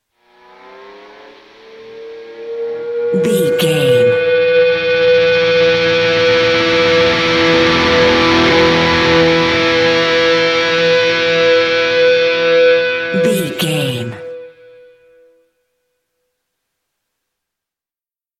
Ionian/Major
electric guitar
Slide Guitar